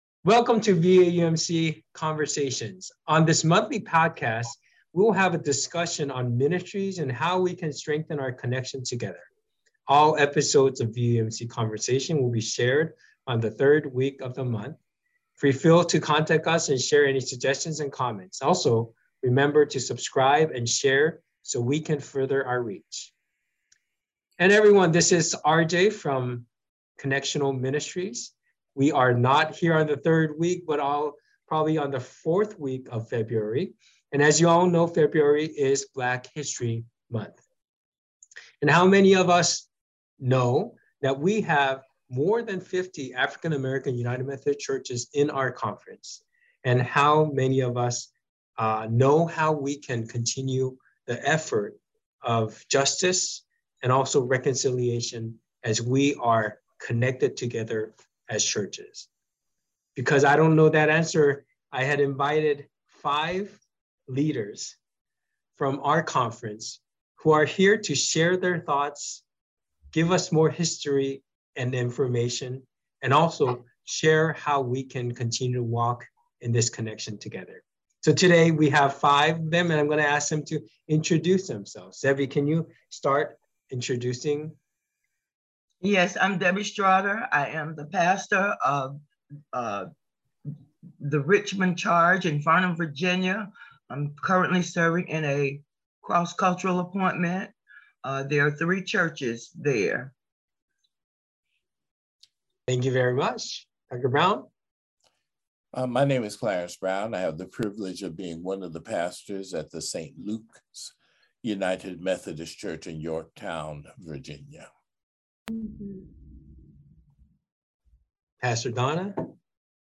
We invite you to listen to this conversation with 5 of our clergy sisters and brother to understand the history, reality, and future of African American Churches in VAUMC.